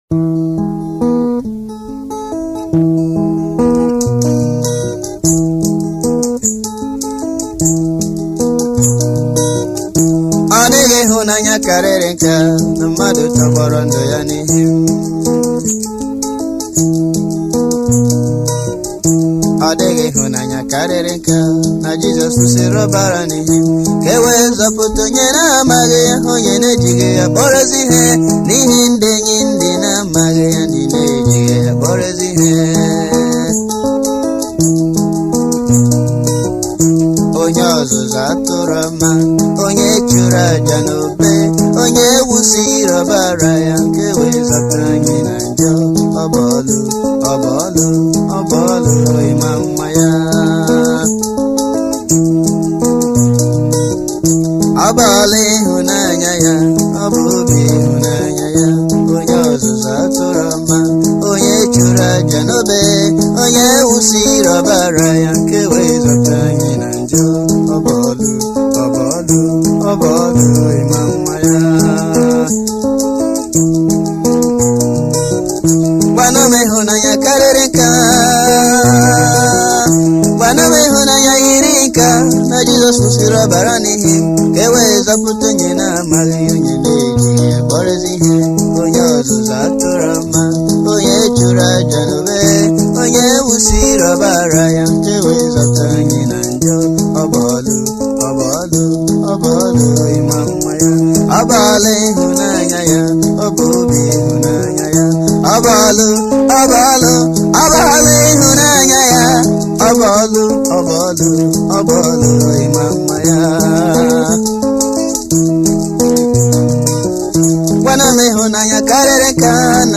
Gospel
Igbo Gospel music